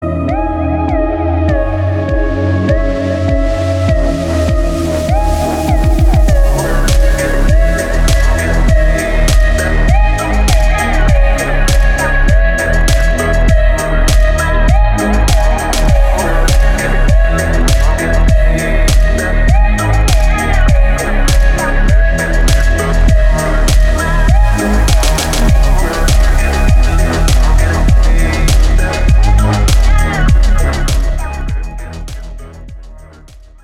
• Качество: 320, Stereo
атмосферные
Electronic
без слов
Chill Trap
расслабляющие
chillwave
Ambient
Кайфовая электронная музыка на рингтон.